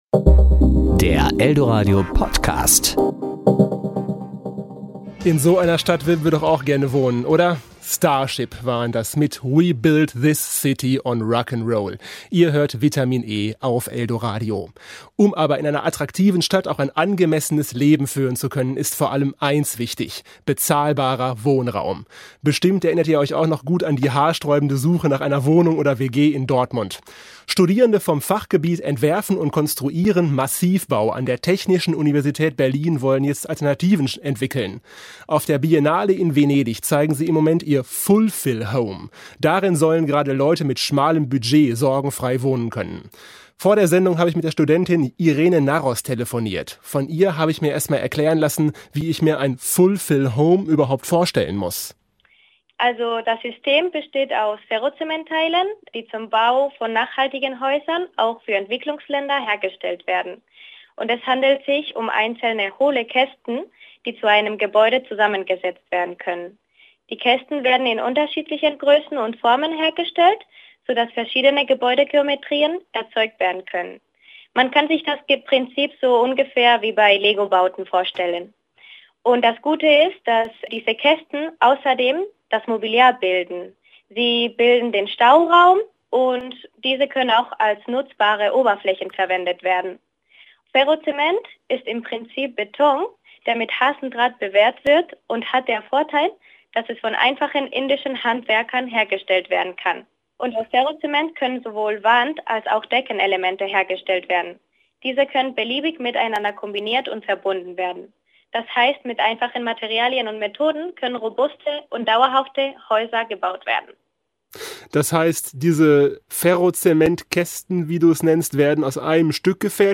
Serie: Interview